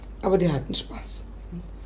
"In der Kürze liegt die Würze" - nach diesem Motto scheinen die Rheinländer im Regiolekt oftmals mit dem Vokal eines Wortes zu verfahren: Statt "Spaß" heißt es hier "Spass" und "gekriegt" wird zu "gekricht".
1. Bad, Rad, Spaß, gibst, grob, schon: In einsilbigen Wörtern, die ein langes a, o, u oder i beinhalten und auf einen Konsonanten (z. B. d, g, t) enden, kann der Vokal gekürzt werden.
Spass (Köln).
orfgen1_vokalkuerzung_spass.mp3